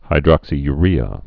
(hī-drŏksē-y-rēə)